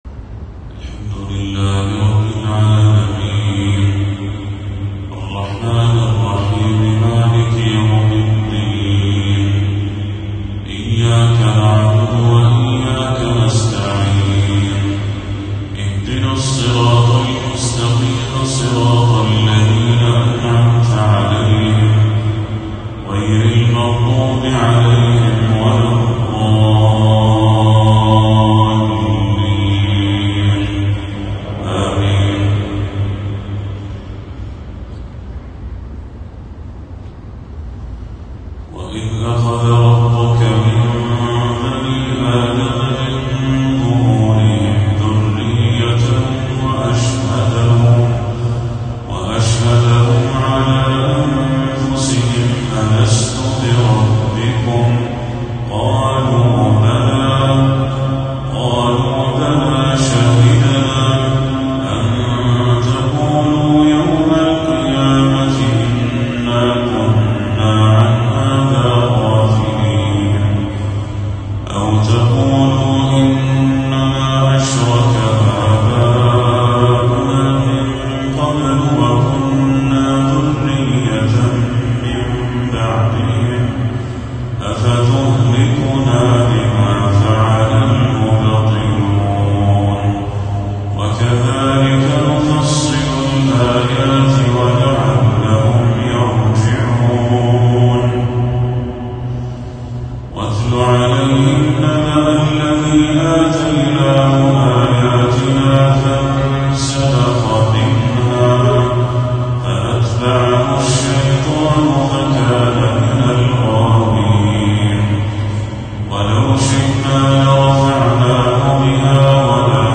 تلاوة رائعة من سورة الأعراف